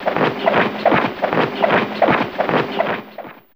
WhirlA4.wav